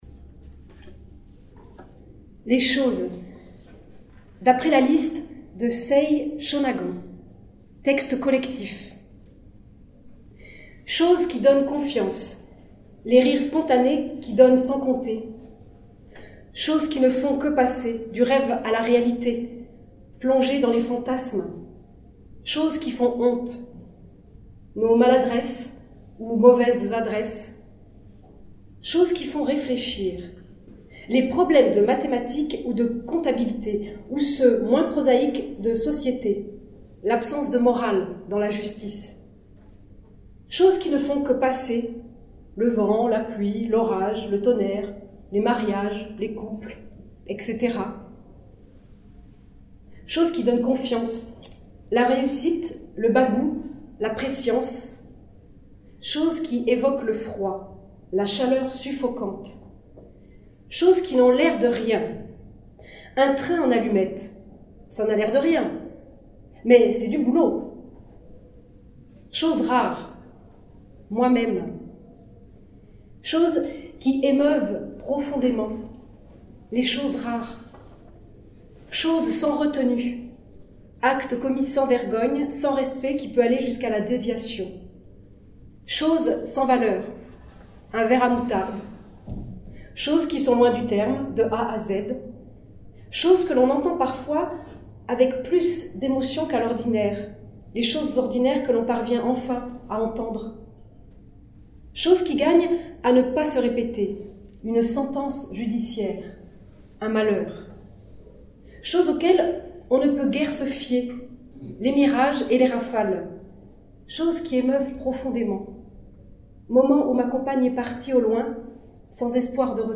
comédienne du théâtre de Romette, est venue lire quelques extraits.